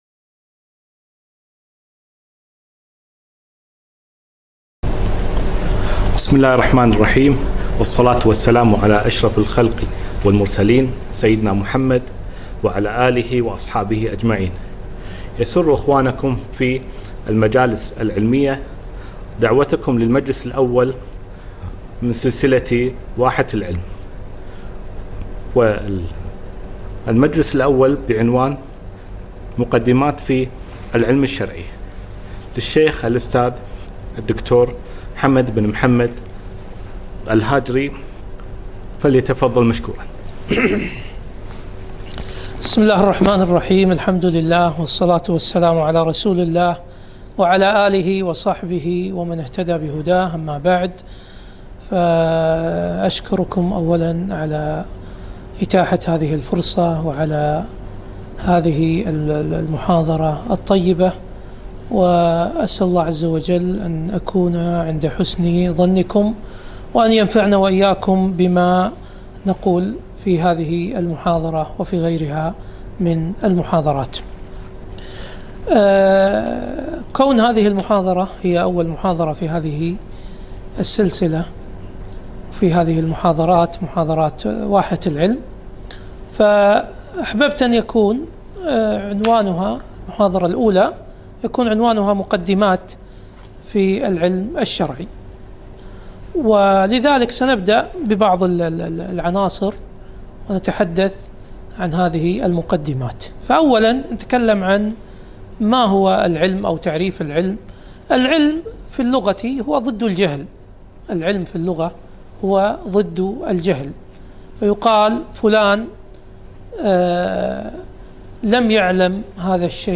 محاضرة - مقدمات في العلم الشرعي